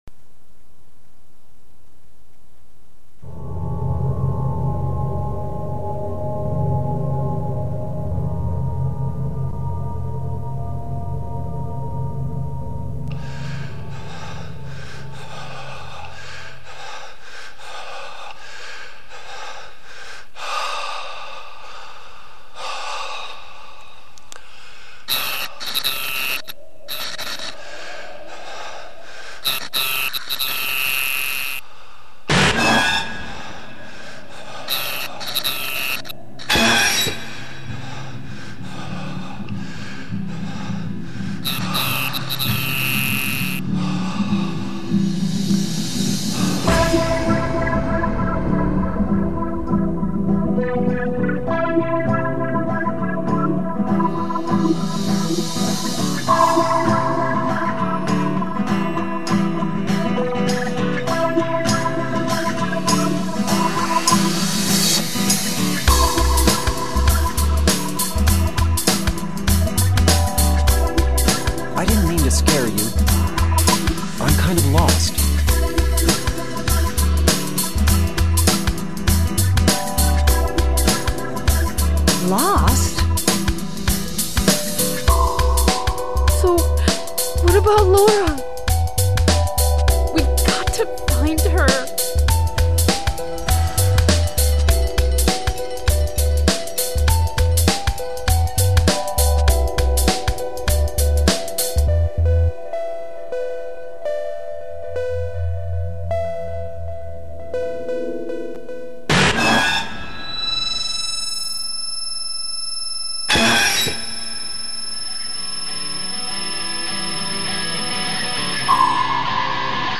Trip-Hop Long